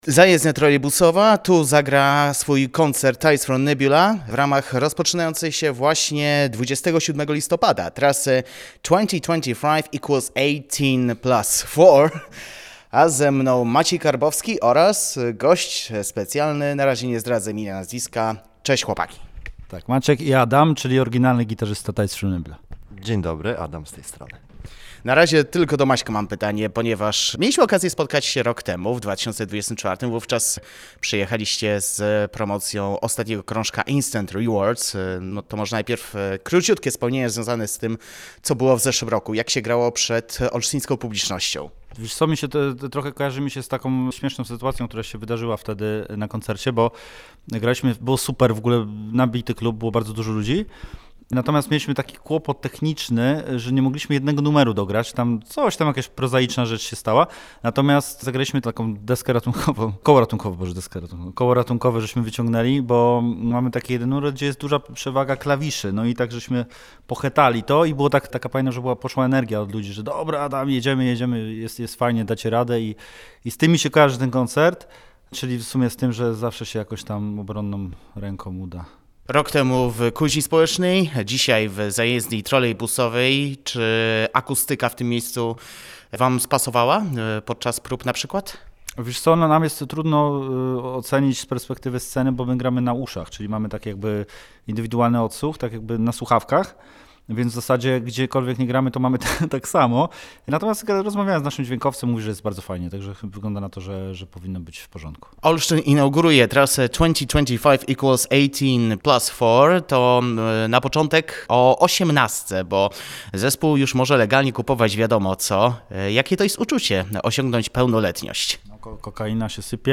Więcej o trasie, a także o nadchodzących planach w rozmowie